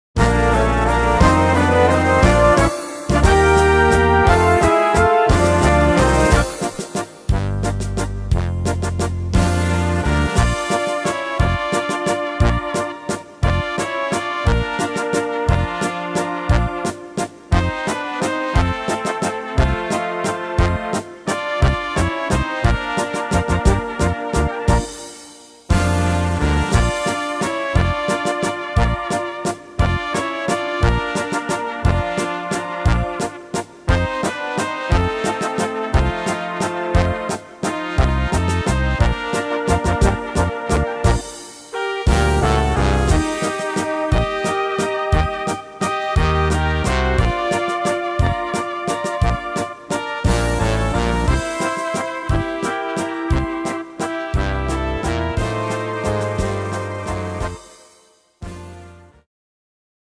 Tempo: 176 / Tonart: Bb – Dur